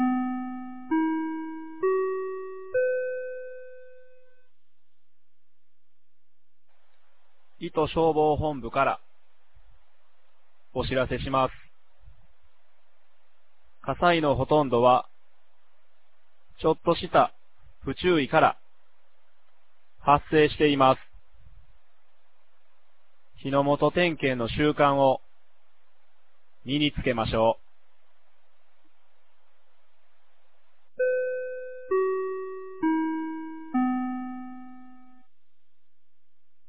2024年05月27日 10時01分に、九度山町より全地区へ放送がありました。